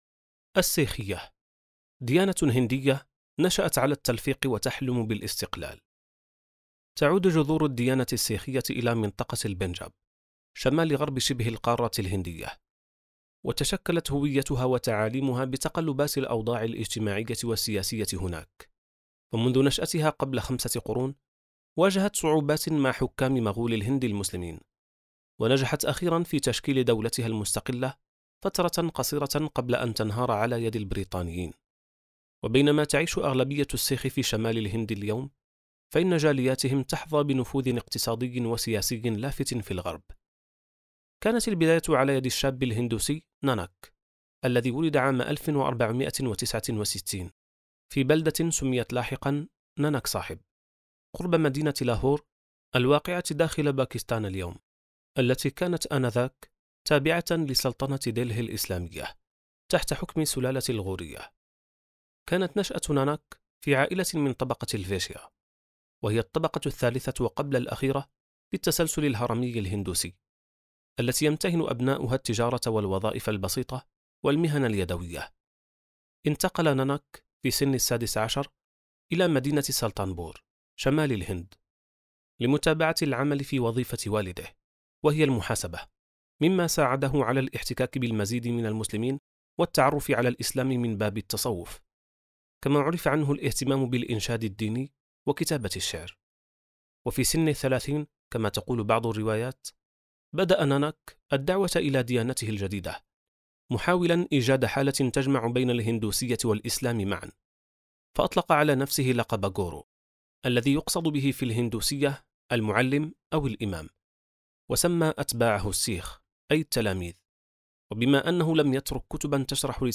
كتاب صوتي | خارطة الطوائف (619): السيخية • السبيل